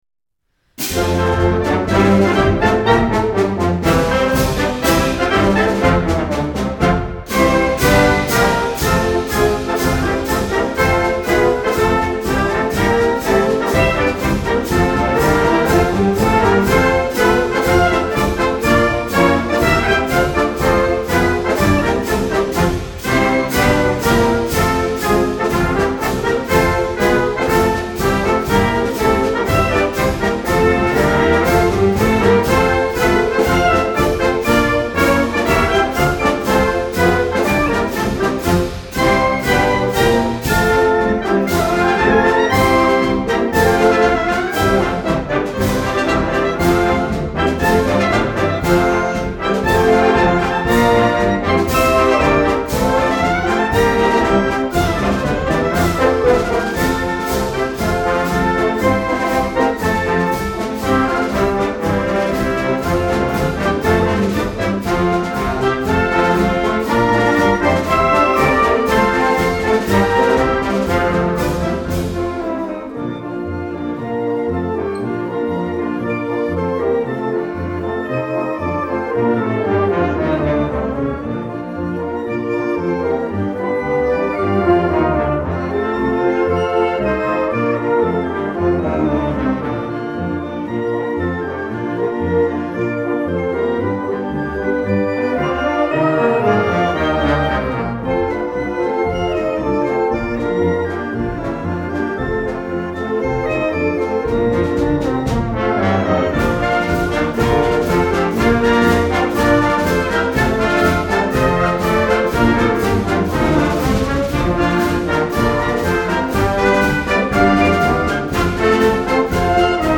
Sounds of Sousa Concert Band w/ Mini Score